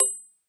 Coins (13).wav